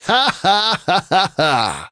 File File history File usage BS_Colt_Unused_Super_Voiceline.wav  (WAV audio file, length 1.9 s, 353 kbps overall) This file is an audio rip from a(n) Android game.
BS_Colt_Unused_Super_Voiceline.wav.ogg